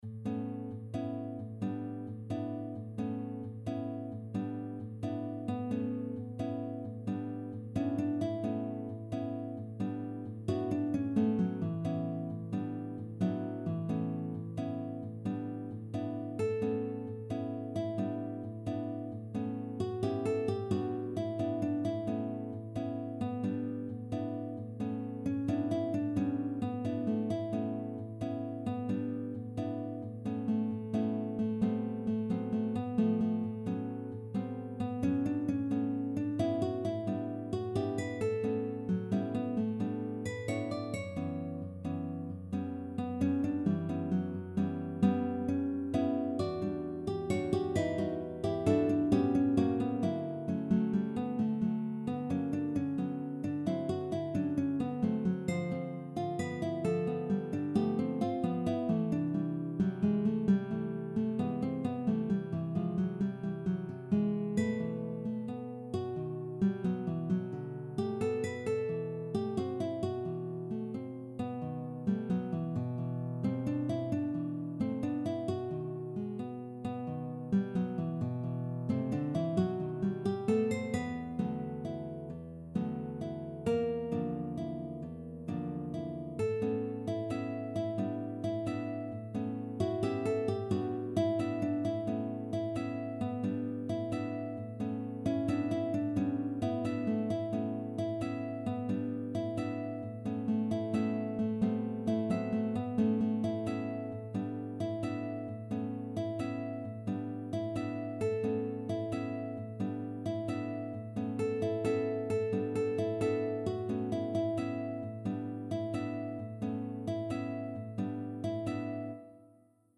(3 guitars)